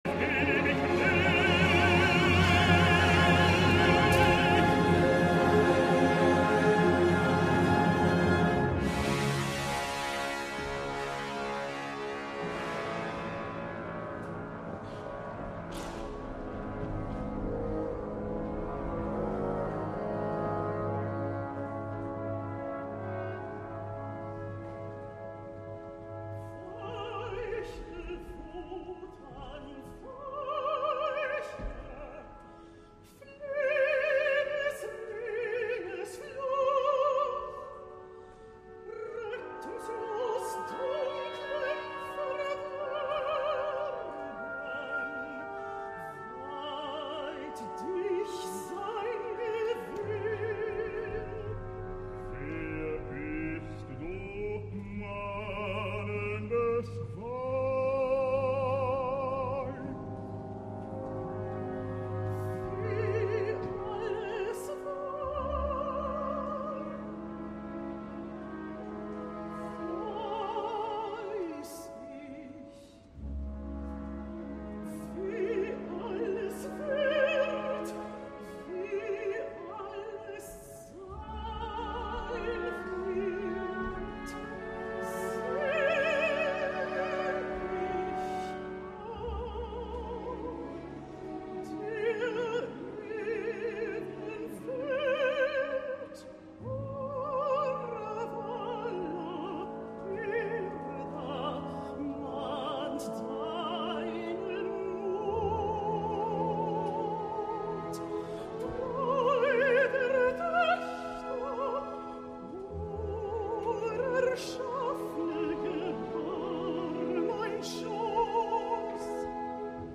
El cas és que amb un so espectacular que hauria de fer avergonyir a més d¡un enginyer de so de les emissores locals, la perfecció sonora de les retransmissions ens permet gaudir detalls orquestral i vocals d’una esplèndida nitidesa.
Kirill Petrenko em va sorprendre i a estones entusiasmar en l’edició del 2013 i aquest any ha començat de manera espectacular en el pròleg, amb una resposta extraordinària d’una orquestra en estat de gràcia, seguint fil per randa, amb alguna distracció, les indicacions bastant alleugerides allà on estem acostumats a certa pompa, però sense deixar de banda l’èpica, el lirisme o la brutalitat que de tot hi ha en aquest compendi del que ens espera en les tres properes jornades.